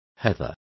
Complete with pronunciation of the translation of heather.